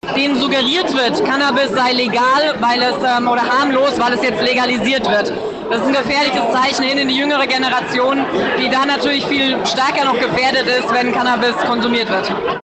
Trotzdem befürchtet sie negative Konsequenzen für die Jugend: Judith Gerlach, Bayerns Gesundheitsministerin